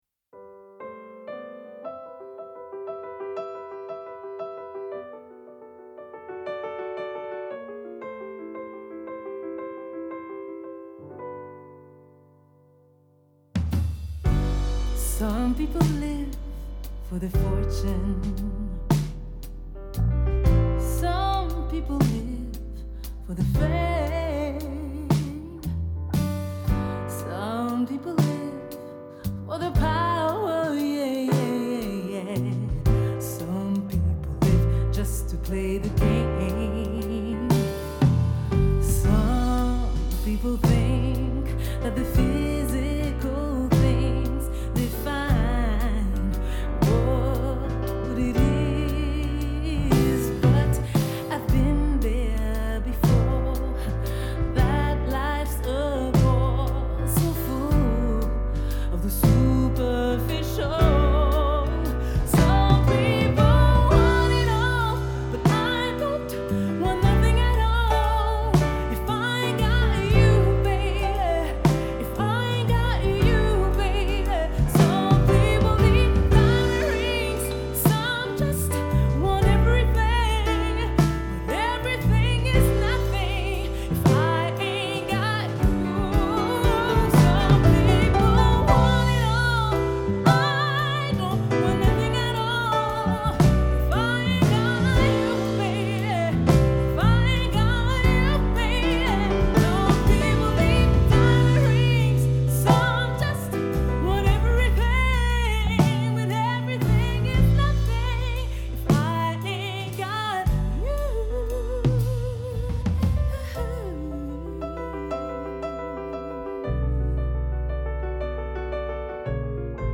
Quartett